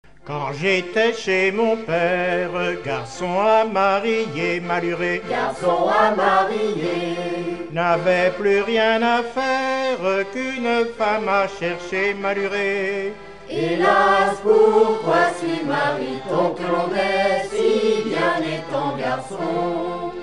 circonstance : fiançaille, noce ;
Genre laisse
Pièce musicale inédite